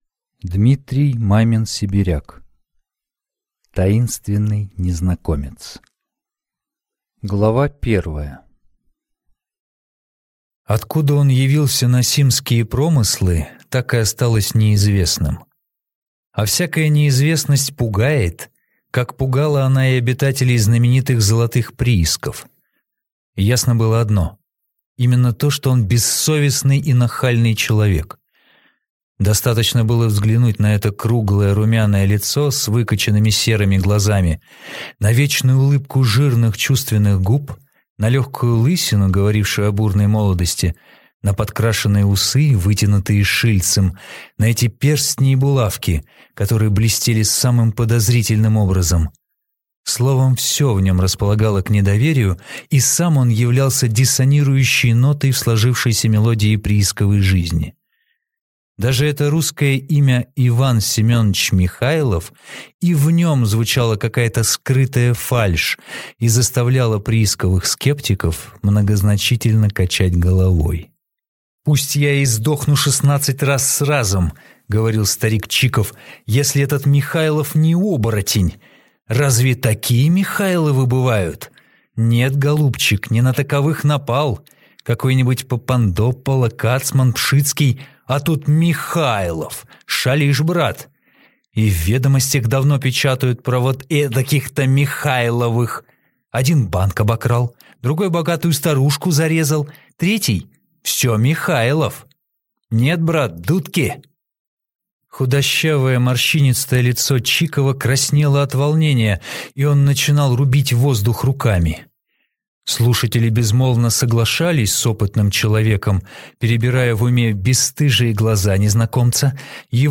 Аудиокнига Таинственный незнакомец | Библиотека аудиокниг